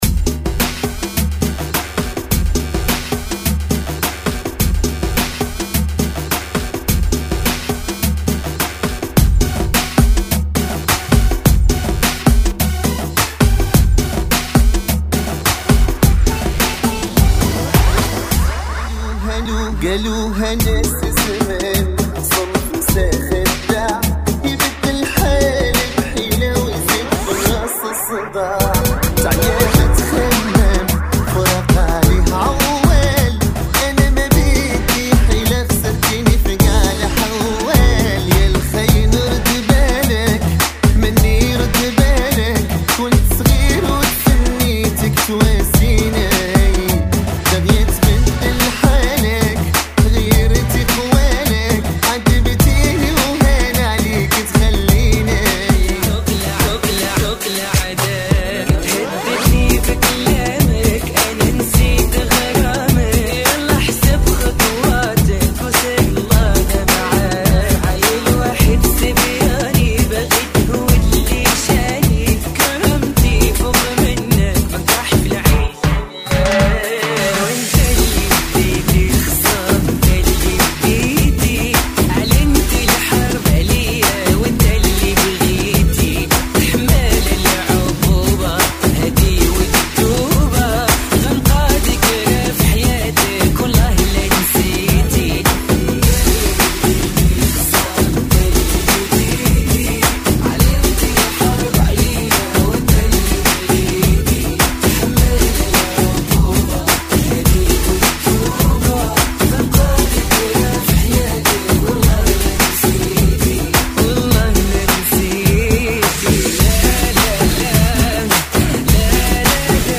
Funky [ 105 Bpm ]